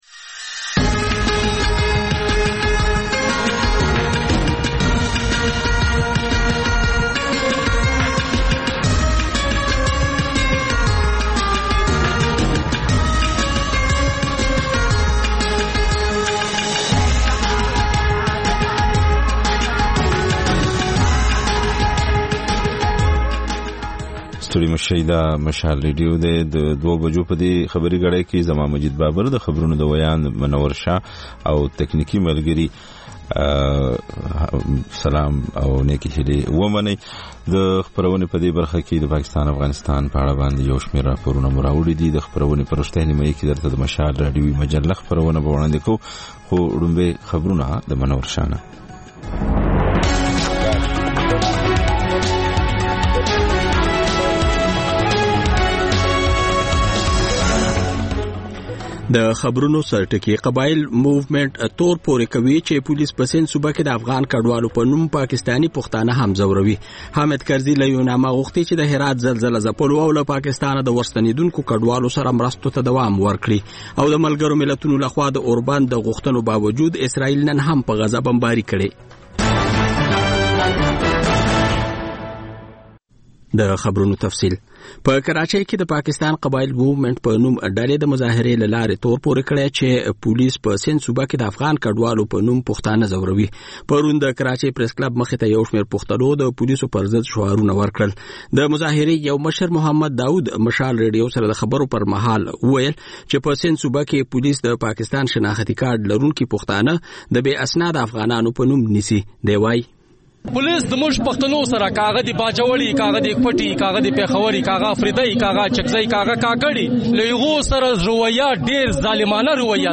د مشال راډیو دویمه ماسپښینۍ خپرونه. په دې خپرونه کې لومړی خبرونه او بیا ځانګړې خپرونې خپرېږي.